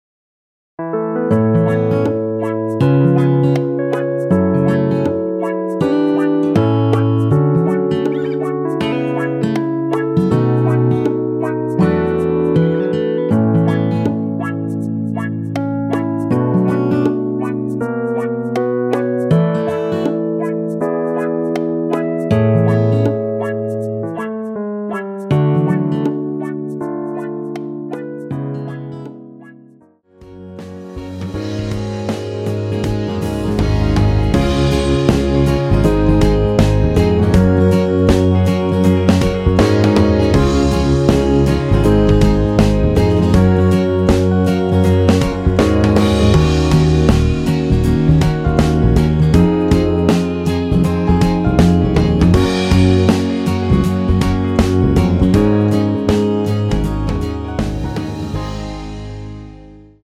MR 입니다.
앞부분30초, 뒷부분30초씩 편집해서 올려 드리고 있습니다.
중간에 음이 끈어지고 다시 나오는 이유는